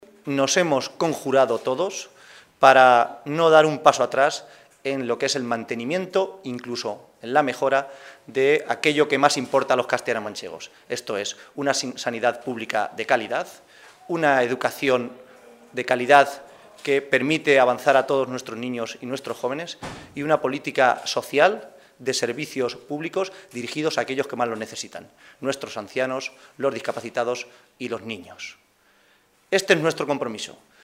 El secretario de Organización del PSOE de C-LM, José Manuel Caballero, ha asegurado hoy, durante la celebración del Comité Regional de este partido, que “los socialistas de C-LM nos hemos conjurado para no dar ni un paso atrás en el mantenimiento y mejora de lo que más importa a los ciudadanos de nuestra Región, que es una sanidad y una educación pública de calidad, y una política social dirigida a los que más lo necesitan”.